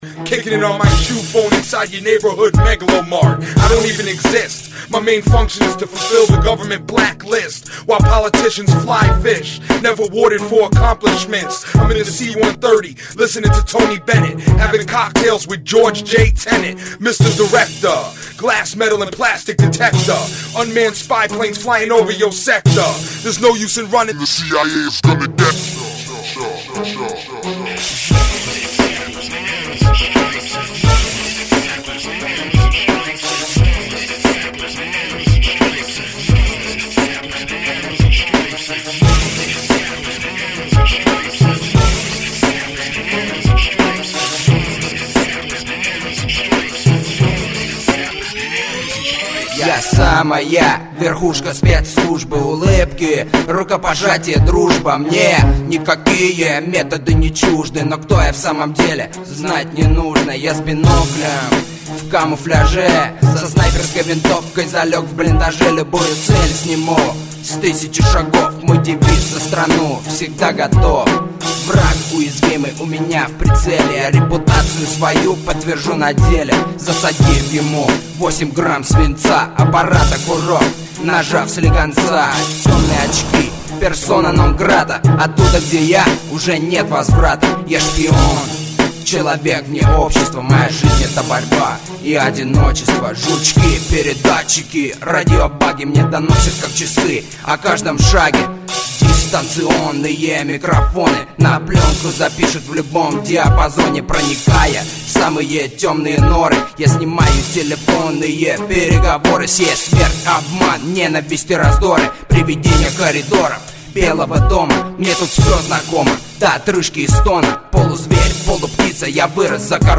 Все делает сам - пишет слова, музыку, сам записывает и исполняет.
Я занимаюсь русским рэпом.